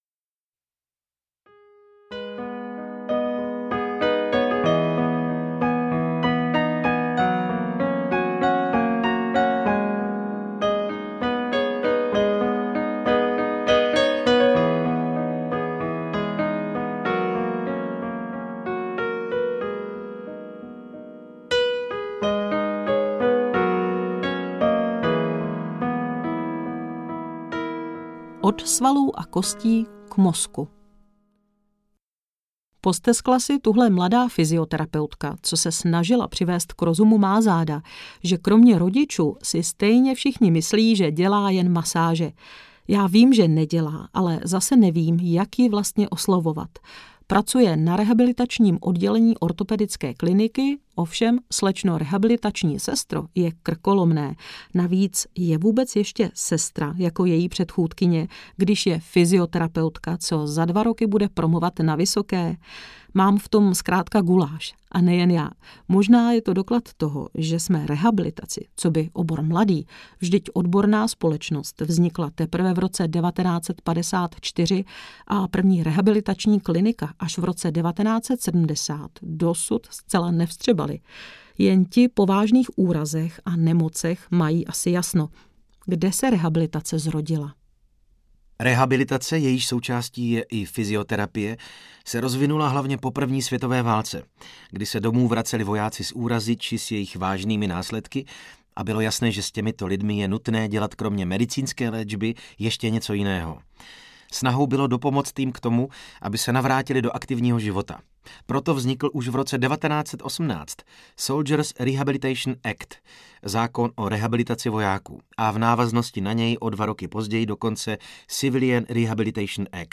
Poslechněte si rozhovor se špičkovým fyzioterapeutem Pavlem Kolářem.
audiokniha